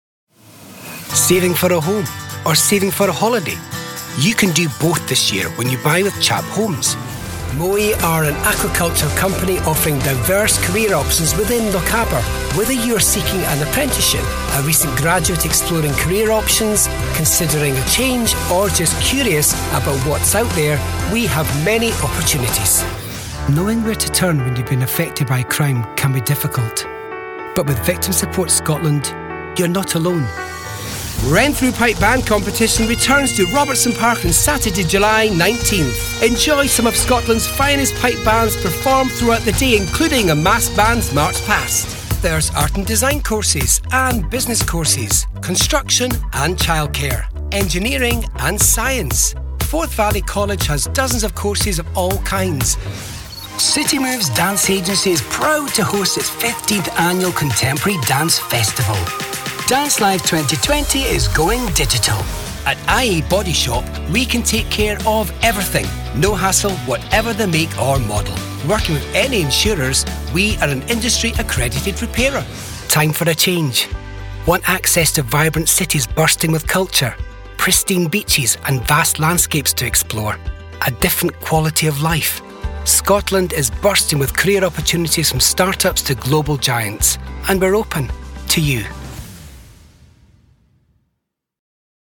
Scottish Voices
20s-30s. Male. Studio. Scottish.
Commercials